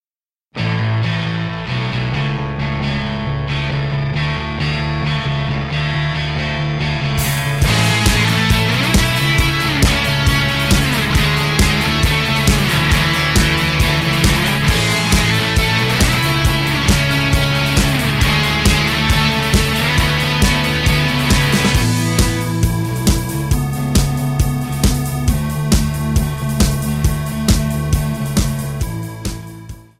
Gb
MPEG 1 Layer 3 (Stereo)
Backing track Karaoke
Pop, Rock, 2000s